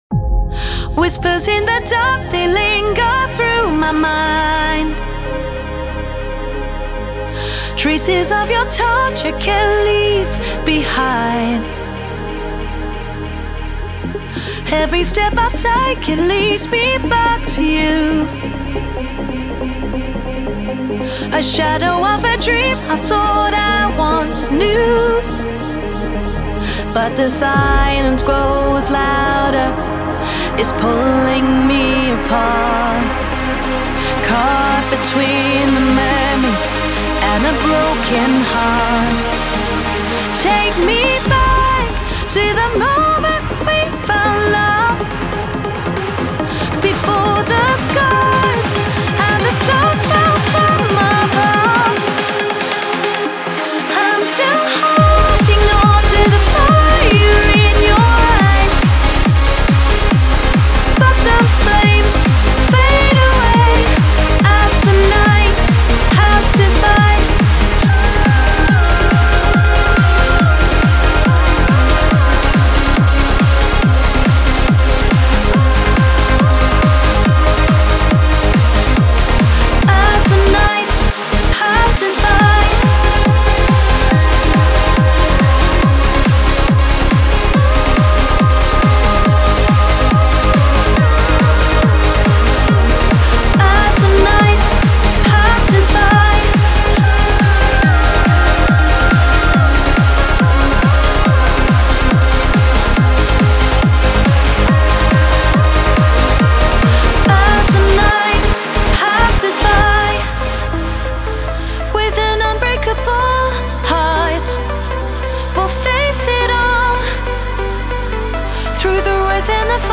Type: Samples
Trance Uplifting Trance Vocals